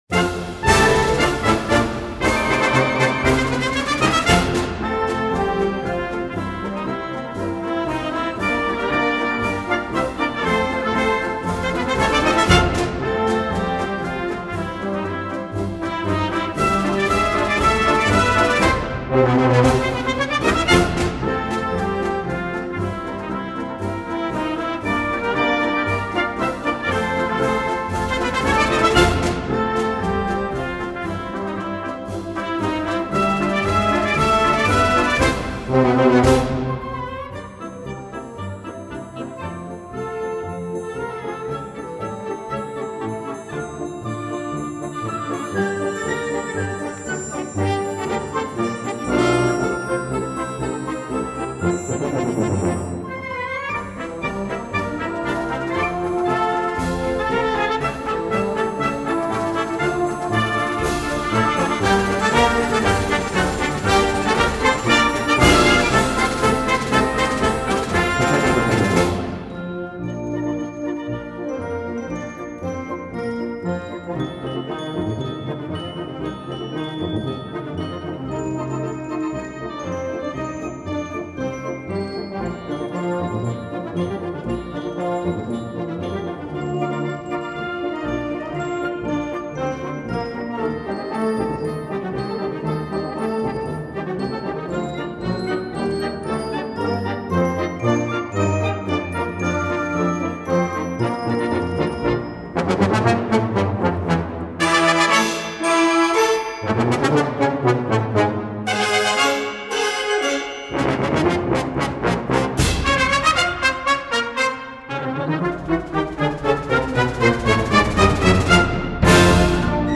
Band → Concert Marches
Voicing: Concert March